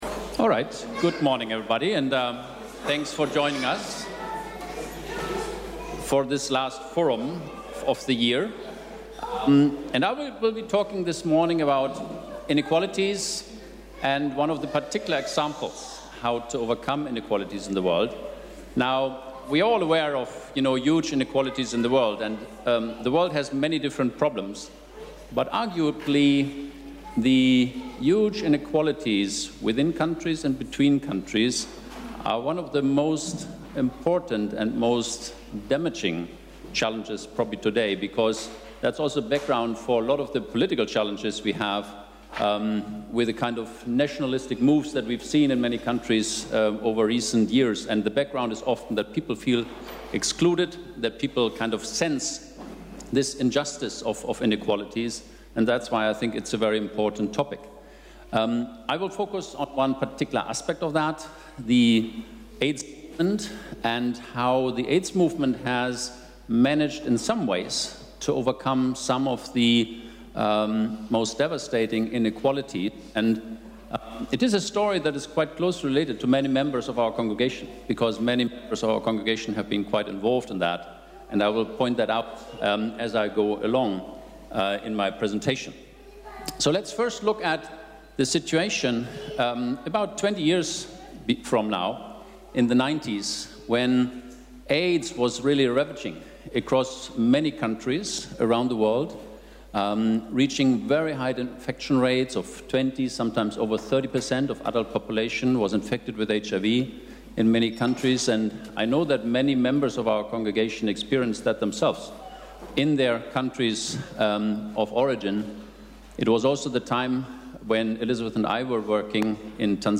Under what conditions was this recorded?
Weekly Forums at ELCG – after worship, in the Sanctuary – 12:45 to 13:30